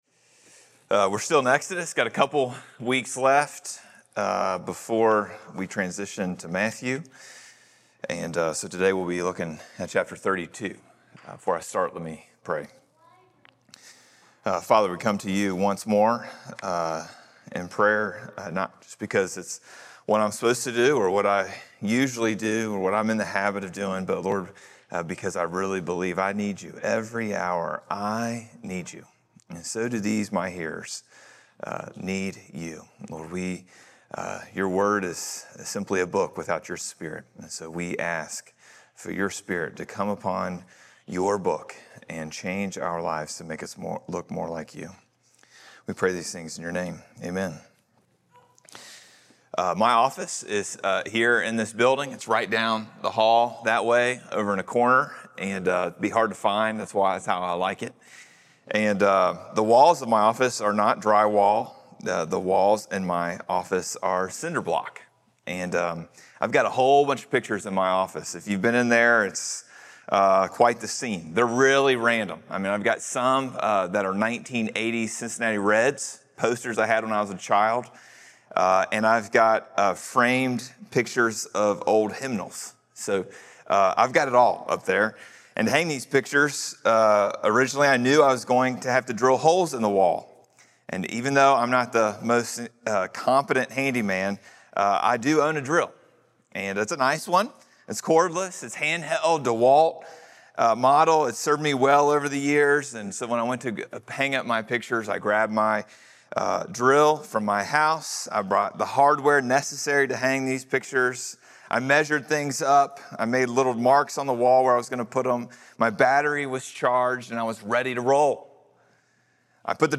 July 6 Sermon. The Joy Is Worth the Cost.
Sermons from Hope Presbyterian Church in Lexington, Kentucky.